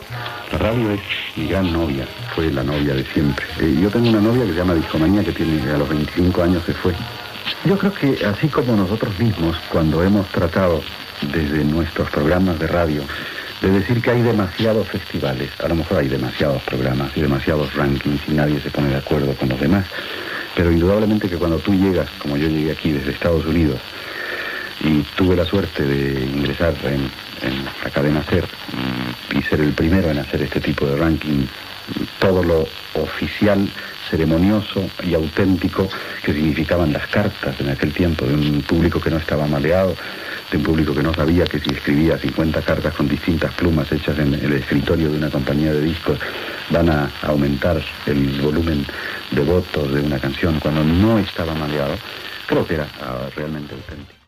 El presentador Raúl Matas parla del programa "Discomanía" de la Cadena SER i de les llistes d'exits musicals
Entreteniment